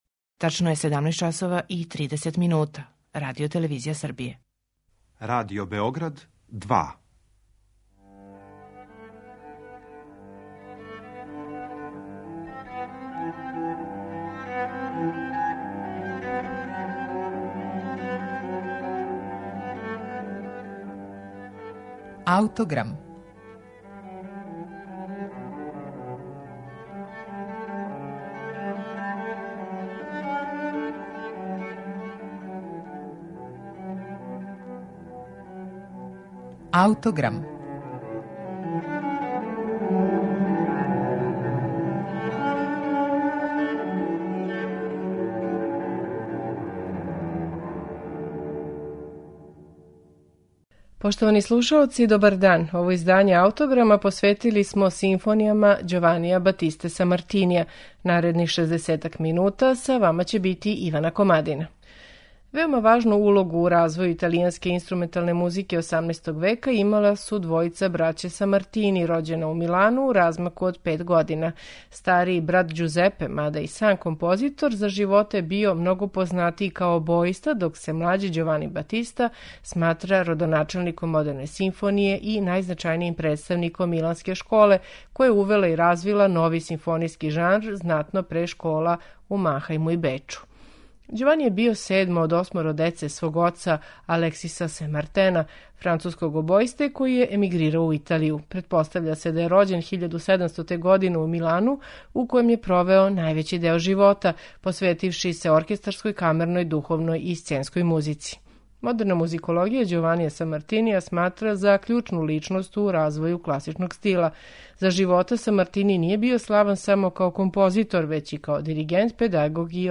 на оригиналним инструментима епохе